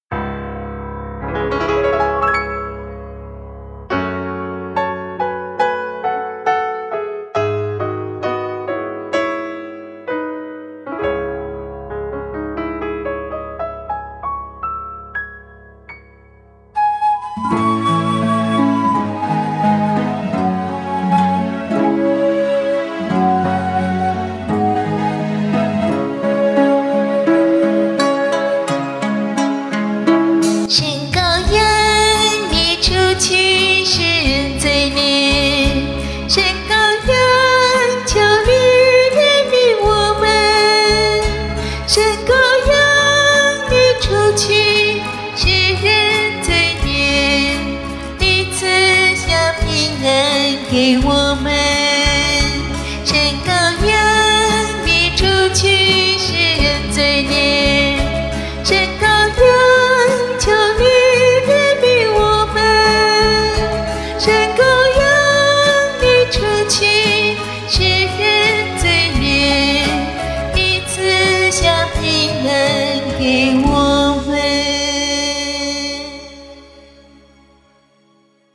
女声献唱：
神羔羊（女声）.mp3